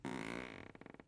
木头吱吱声11
描述：木制CreakingWooden椅子Creak
Tag: 地板吱吱作响 椅子 咯吱 木质